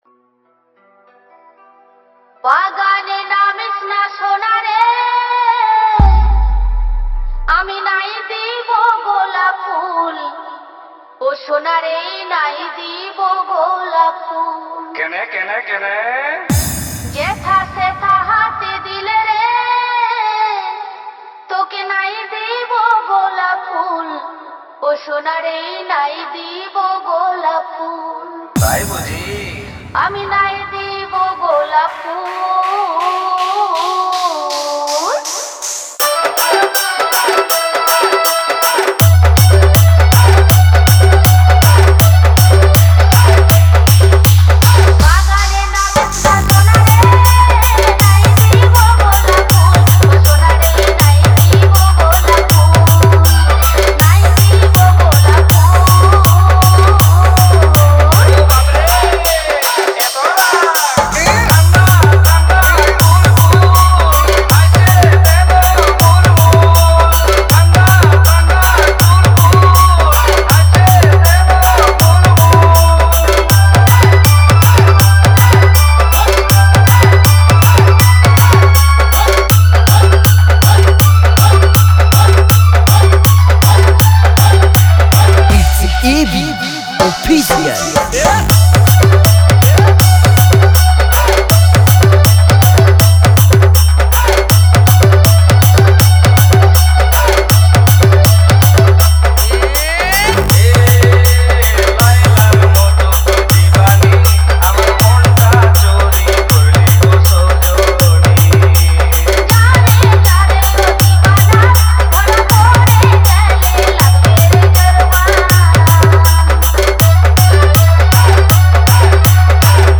Dj Remixer
Purulia Dj Remix